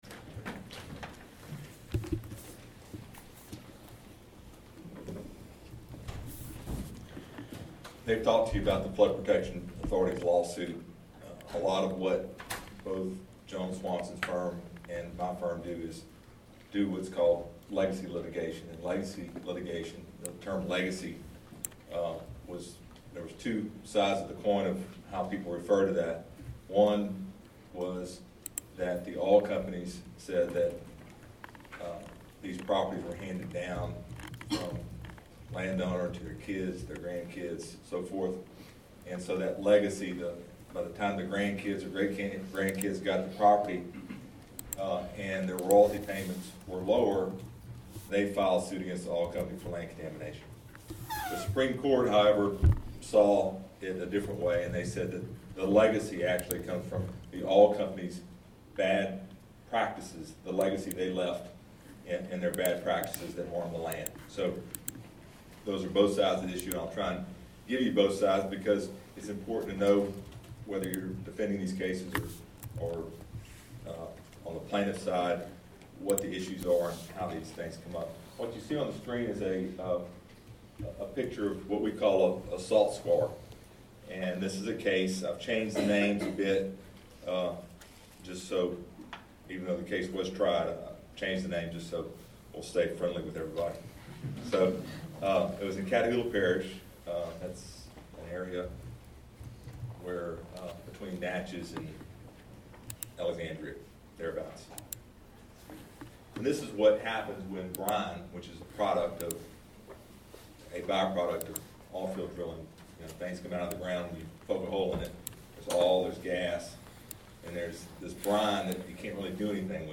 from TELS 2015 on the decades of litigation from LA oil and gas fields